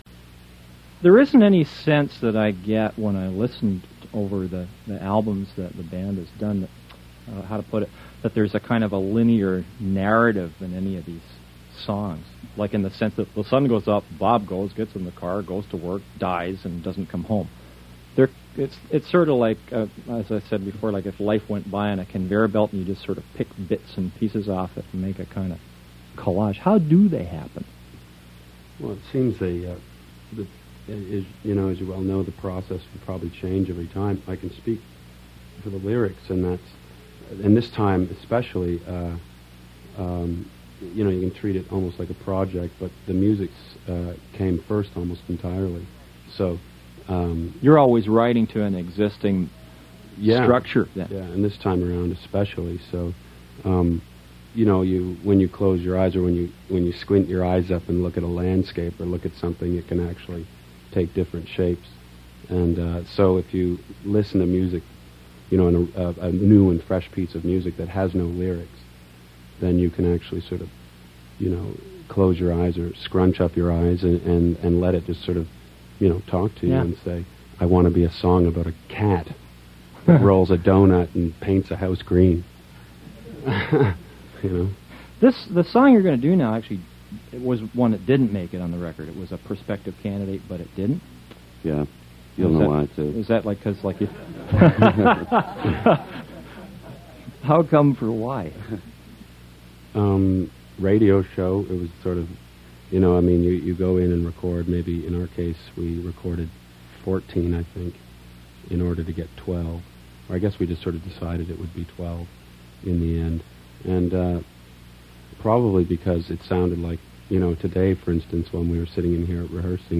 Source: Radio